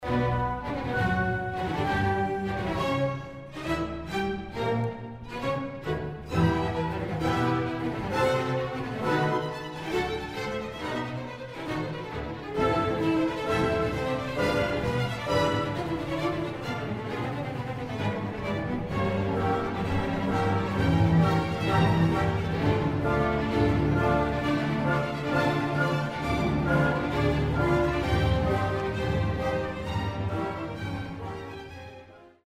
There is some real contrapuntal turmoil in the development.
Example 7 – Counterpoint in development :